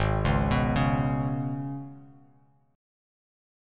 G#mbb5 chord